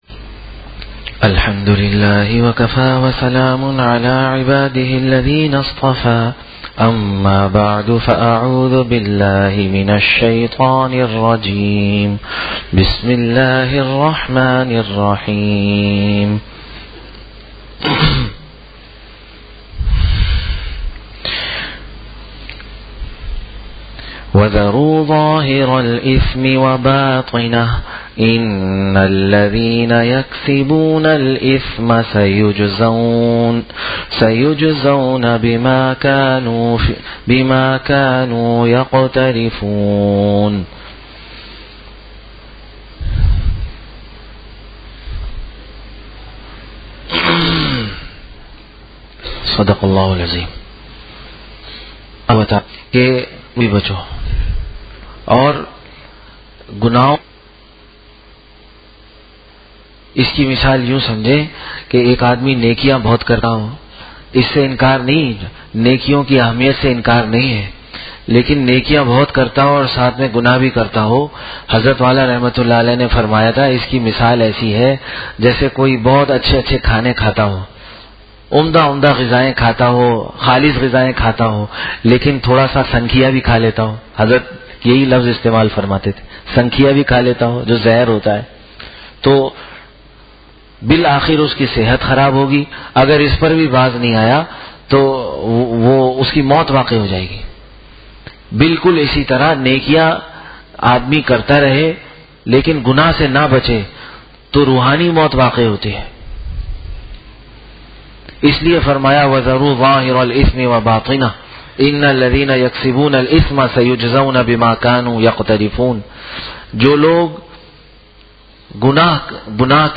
Bayan Monday 05-Jan-2015 (Gulshan Khanqah)